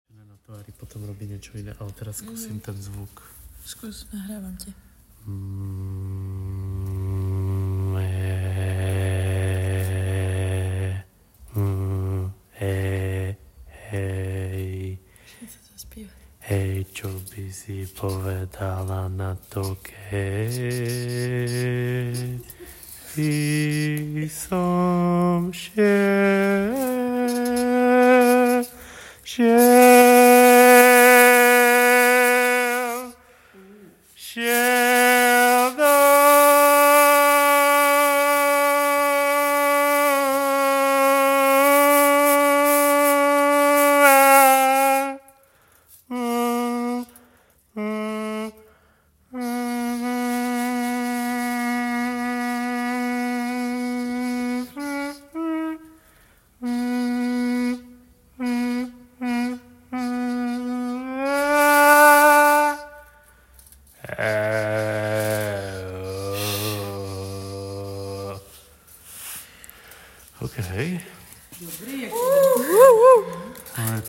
This appeared during an improvisation based on I don’t remember what exactly. The sound efect you can hear is caused by a mask made of aluminium foil. ContentConcept en resonance en mask en singing File Date en 2025-03-12 Type en Audio Tier en 3.